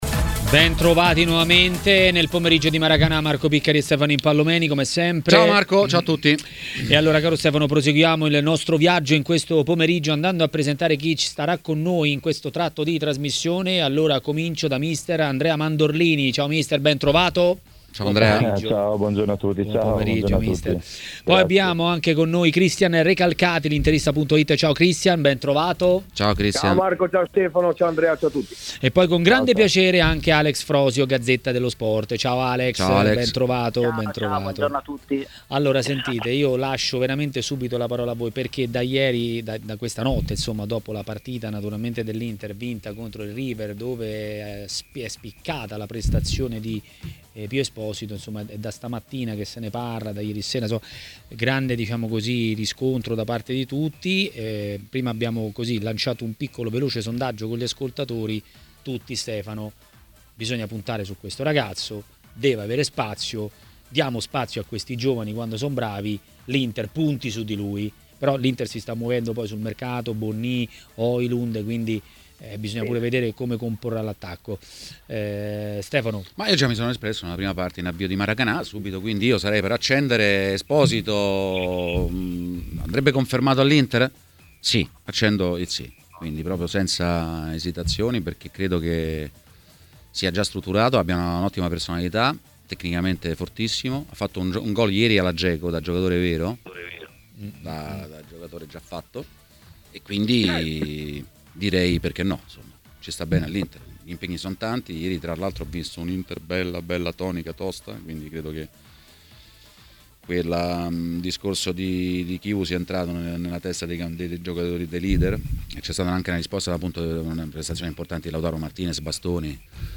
A intervenire a TMW Radio, durante Maracanà, è stato mister Andrea Mandorlini.